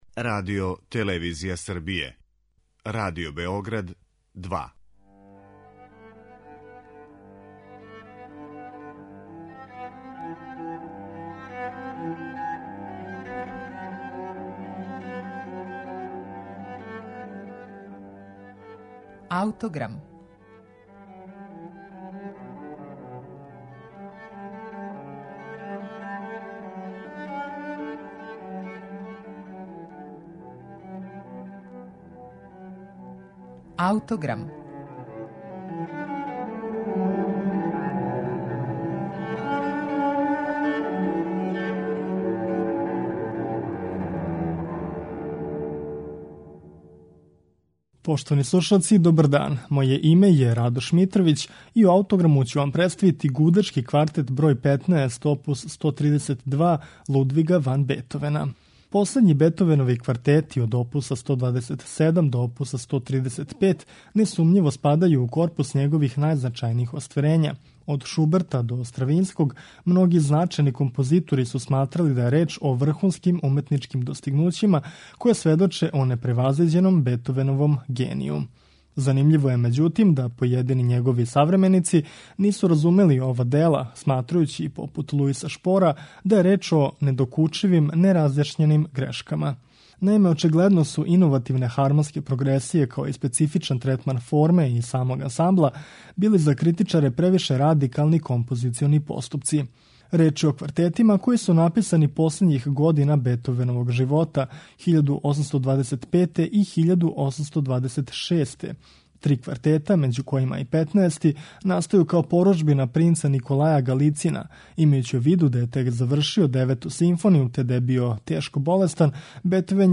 БЕТОВЕН: ГУДАЧКИ КВАРТЕТ ОП 132
У емисији Аутограм, представићемо Гудачки квартет опус 132, број 15, у извођењу квартета Албан Берг.